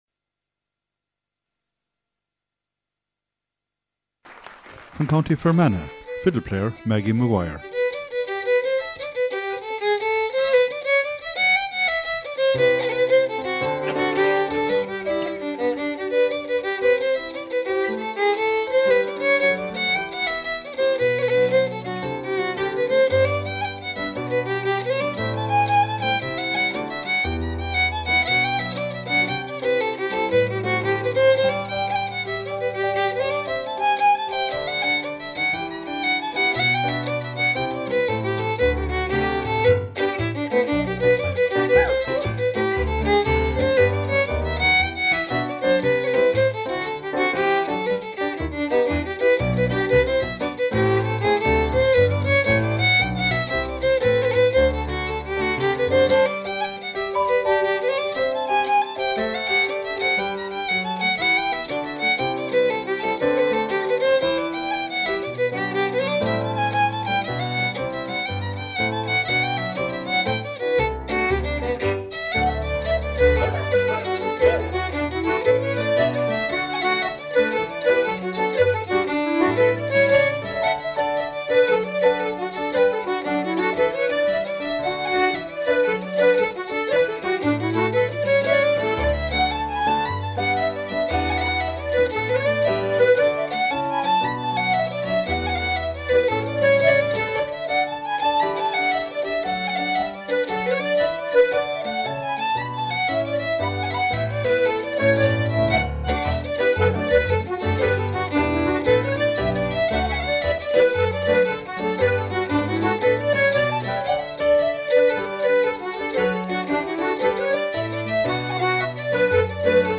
two jigs
in the Comhaltas Concert Tour of Britain 2014
Concert, Tour, Fiddle, Accordion, Piano, Jig, ComhaltasLive.